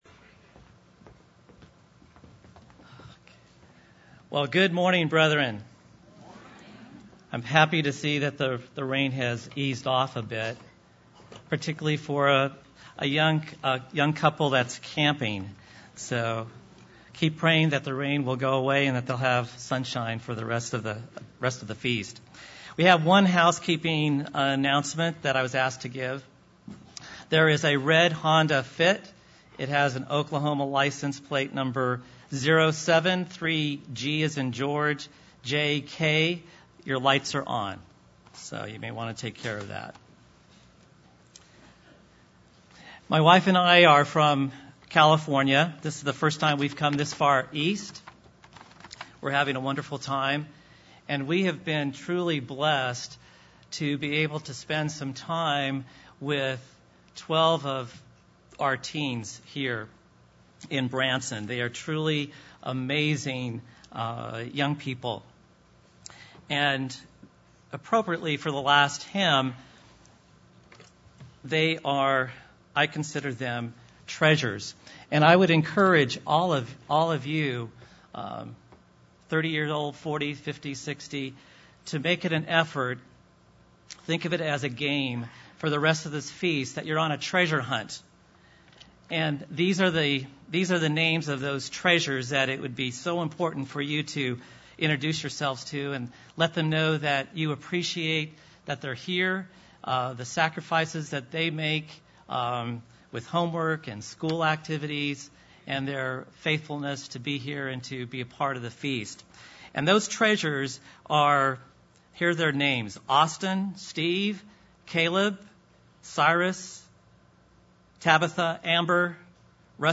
This sermon was given at the Branson, Missouri 2014 Feast site.